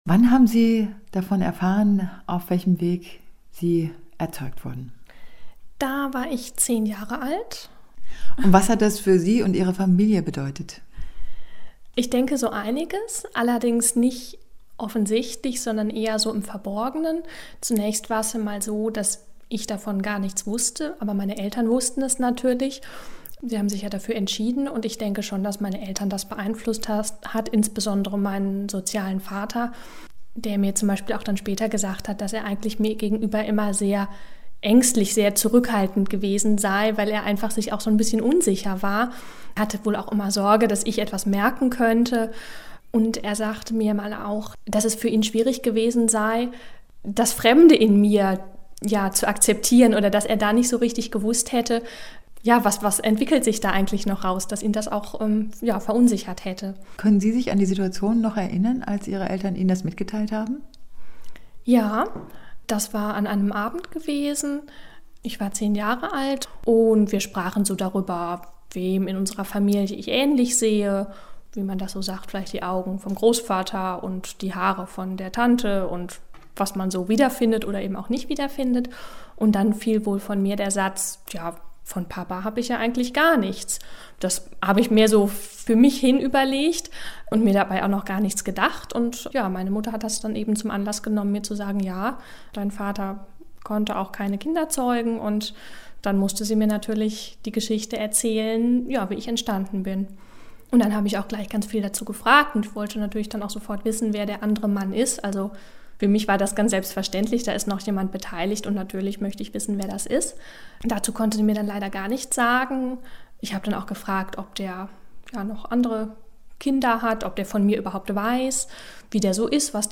Interview